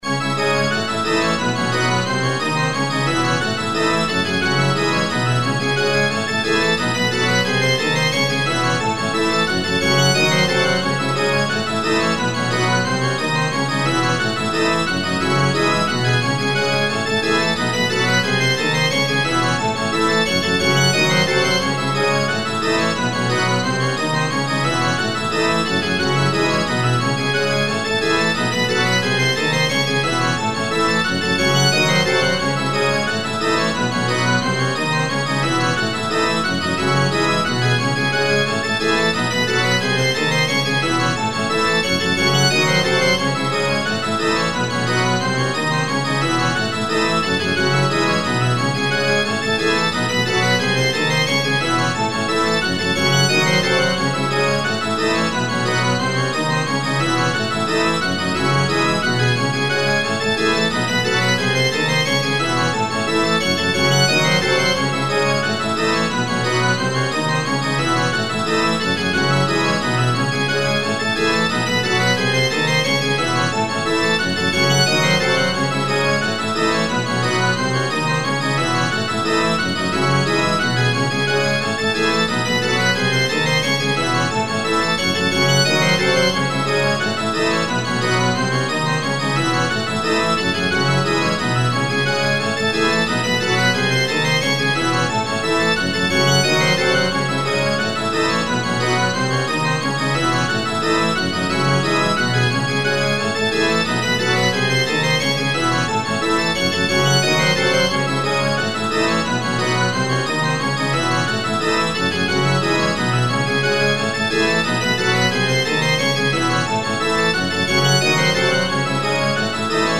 LOOP推奨： LOOP推奨
楽曲の曲調： MIDIUM